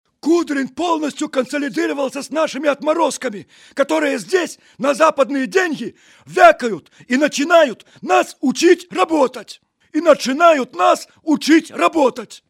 пародия на голос Лукашенко, Александра Григорьевича
Категория: пародии
Характеристика: Пародист